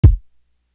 NB Kick.wav